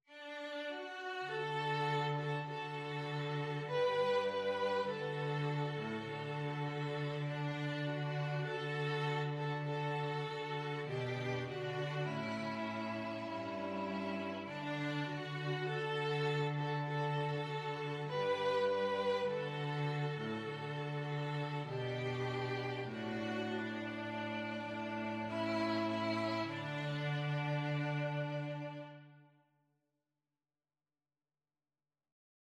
Free Sheet music for String trio
ViolinViolaCello
D major (Sounding Pitch) (View more D major Music for String trio )
Cantabile =c.100
4/4 (View more 4/4 Music)
Traditional (View more Traditional String trio Music)